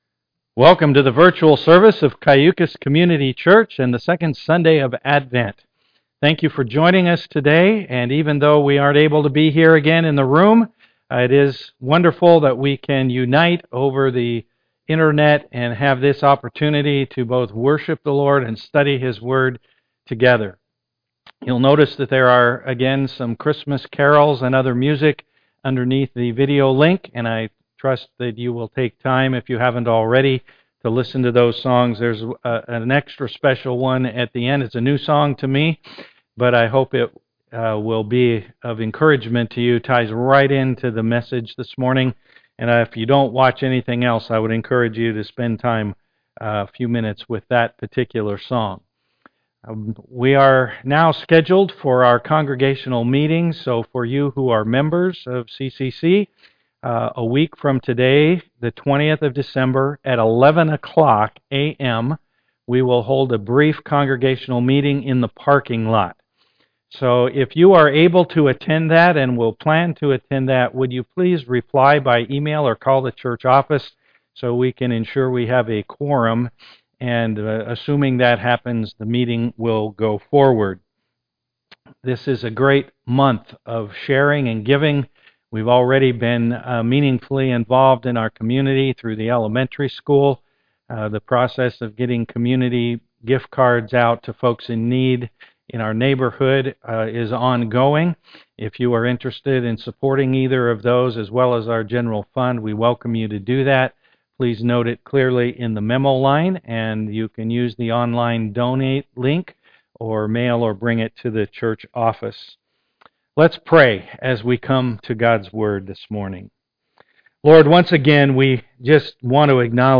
Galatians 4:4 Service Type: am worship Click on the links below to enjoy a time of worship prior to listening to the message.